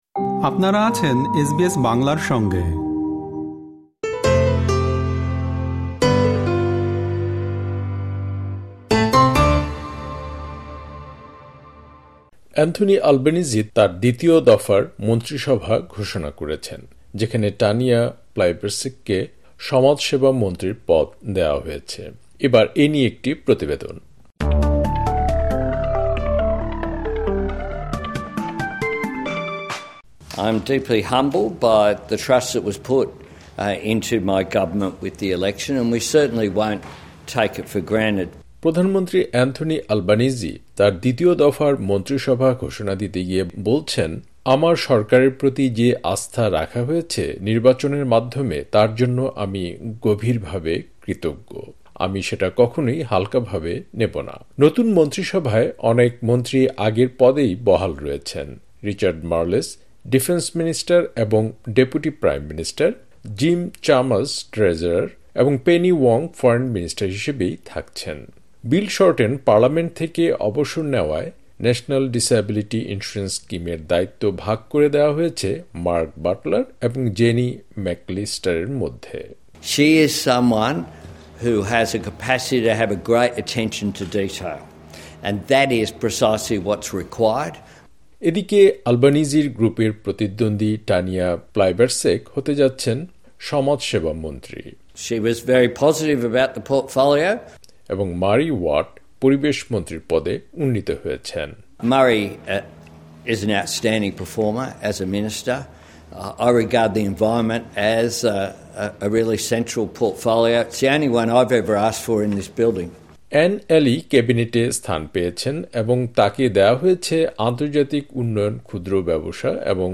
অ্যান্থনি আলবানিজি তার দ্বিতীয় দফার মন্ত্রিসভা ঘোষণা করেছেন, যেখানে টানিয়া প্লাইবারসেককে সমাজসেবা মন্ত্রীর পদ দেওয়া হয়েছে। এবার এ নিয়ে একটি প্রতিবেদন।